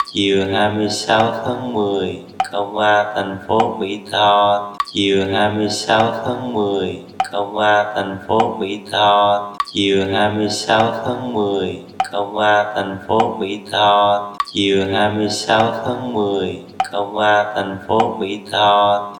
But don’t forget, we’re only at 50% of full speed.